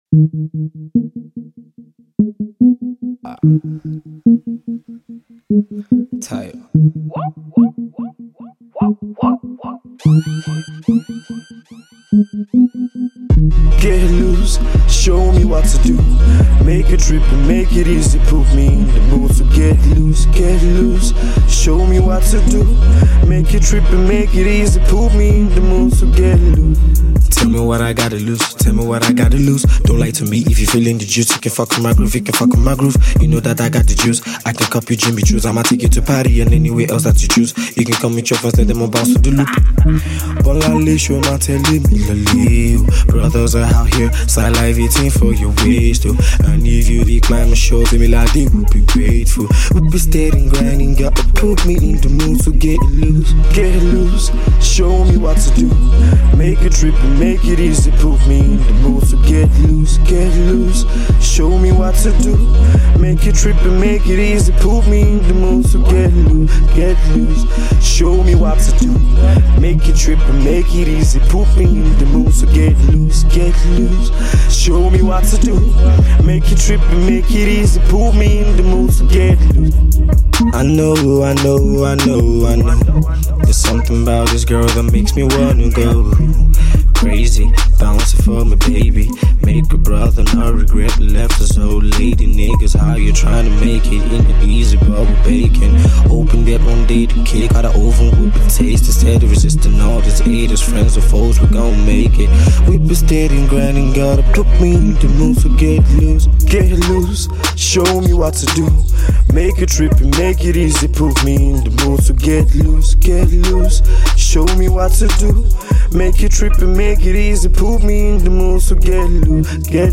Music Genre: RnB/Trap